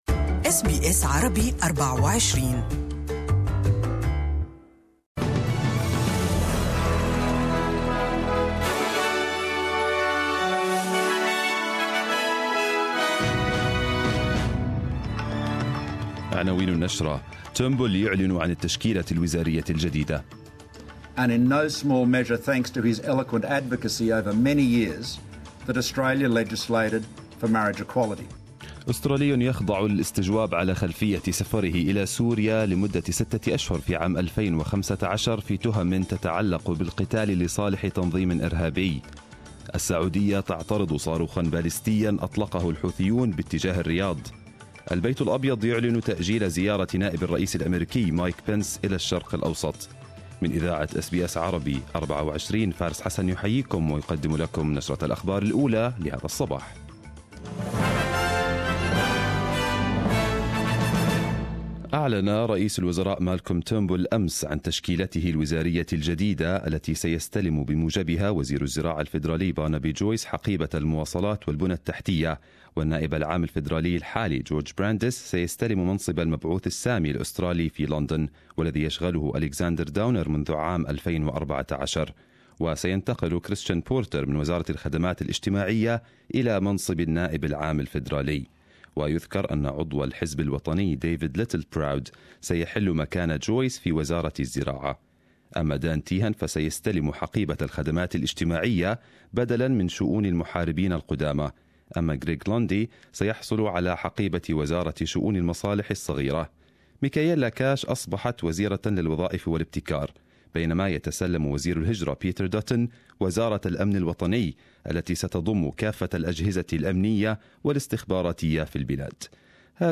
Arabic News Bulletin 20/12/2017